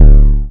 808 (1da).wav